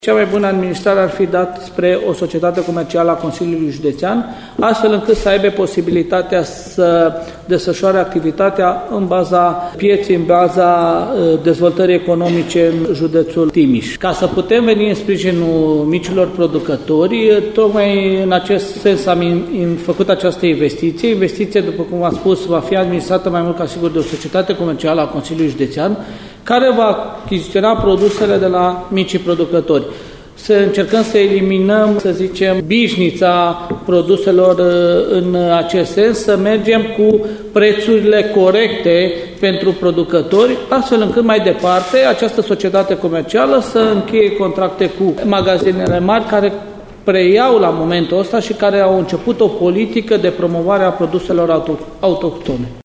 Președintele CJ Timiș a mai declarat că societatea comercială înființată de Consiliul Județean Timiș va avea obligația să obțină un profit minim.
dobra-centrul-de-legume-fructe.mp3